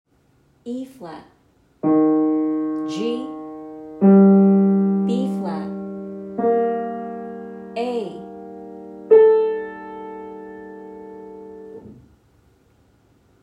Notice that the clarinet’s A is unlike the other notes. Indeed, if you were to hold out E-flat, G, and B-flat, then play A on the piano, it would sound out of place.